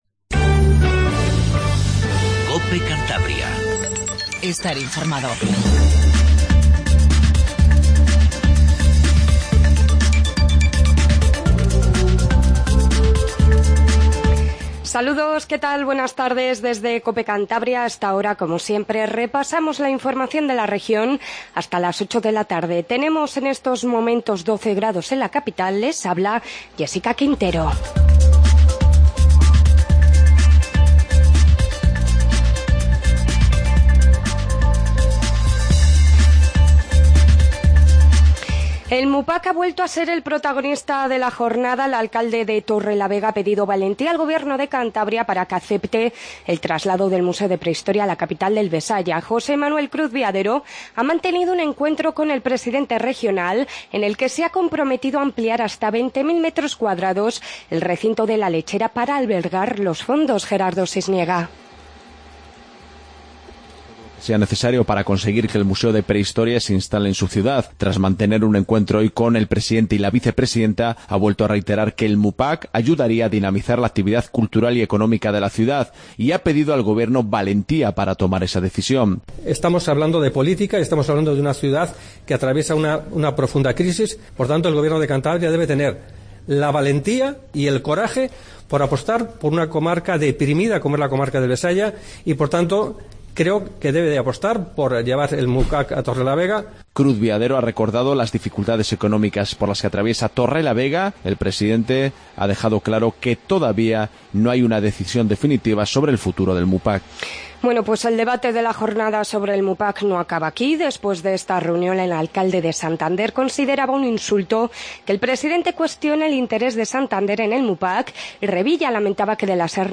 INFORMATIVO DE TARDE 19:50